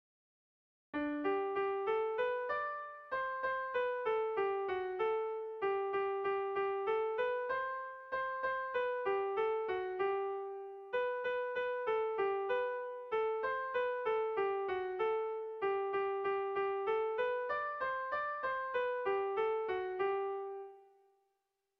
Erlijiozkoa
Zortziko txikia (hg) / Lau puntuko txikia (ip)
A1A2BA2